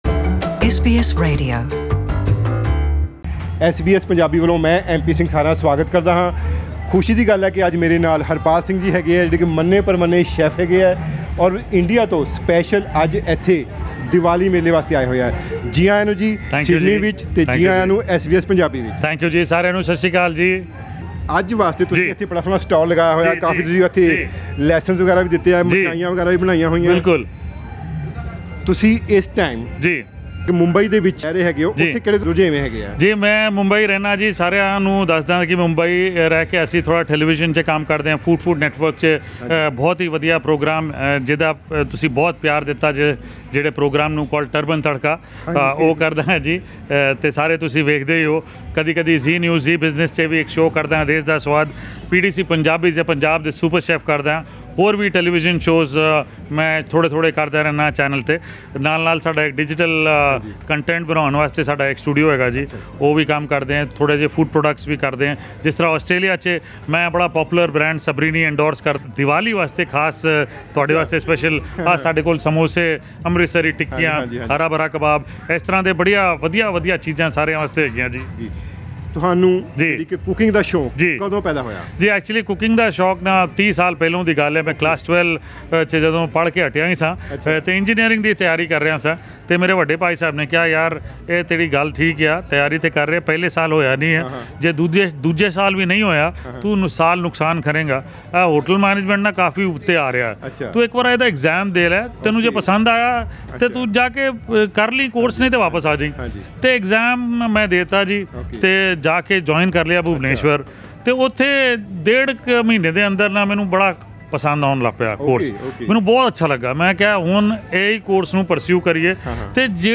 Harpal told SBS Punjabi at Diwali festival in Sydney, ‘It is always a pleasure and honour to cook and serve delicious, homemade and nutritious food to your loved ones.